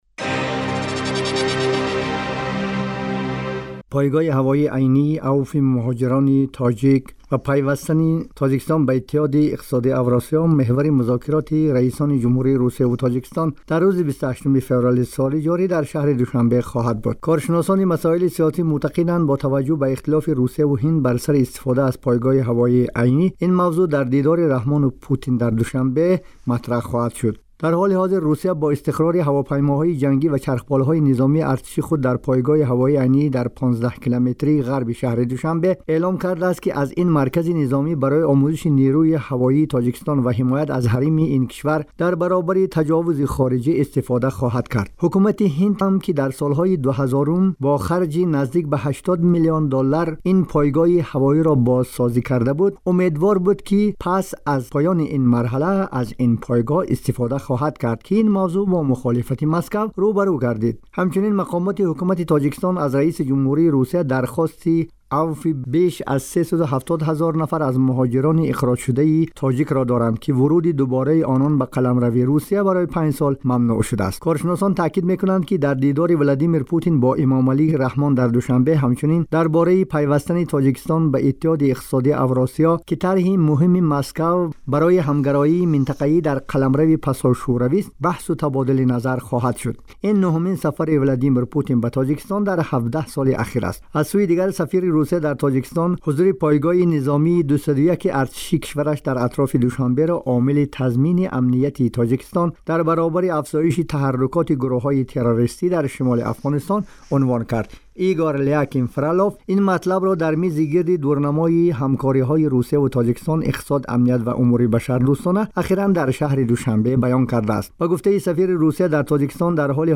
дар гузориши вижа ба ин мавзуъ пардохтааст, ки дар идома мешунавед: